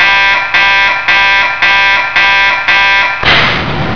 ALARM1.WAV